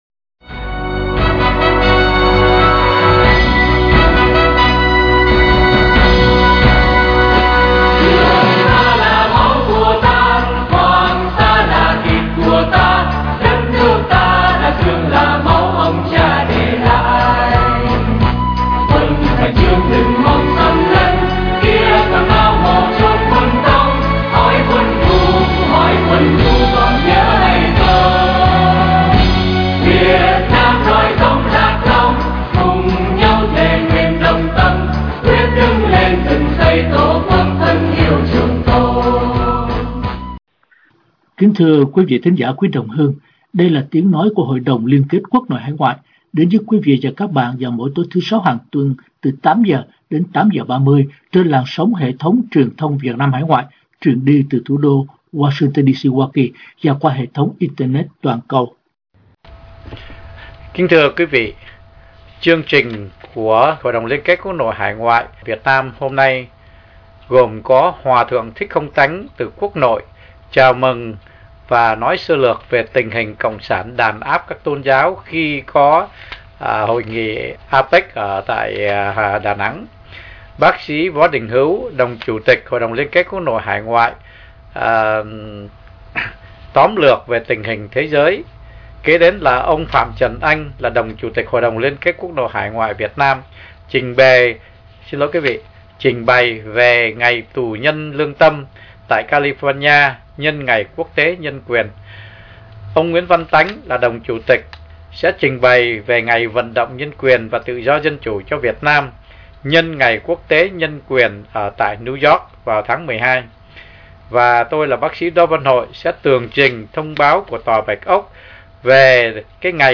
Chương trình phát thanh phát hình của Hội Đồng Liên Kết Quốc Nội Hải Ngoại